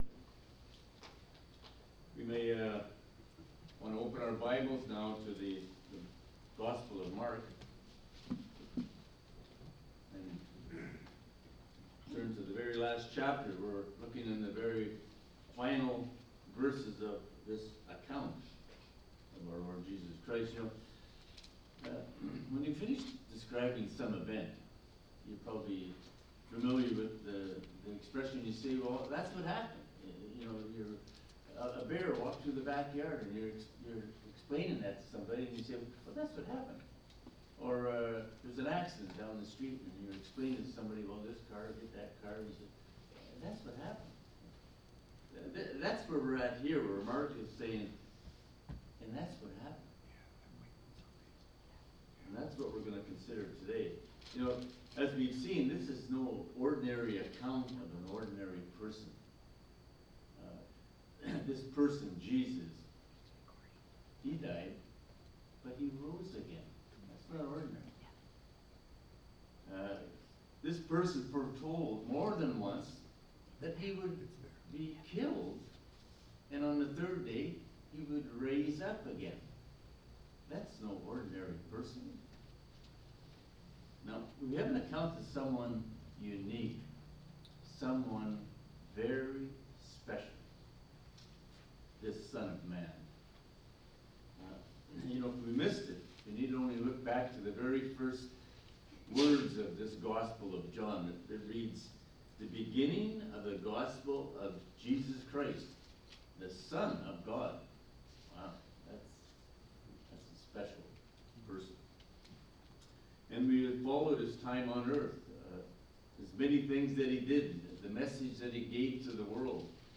Passage: Mark 16:14-20 Service Type: Sunday Morning « Mark 16:9-13 Resurrection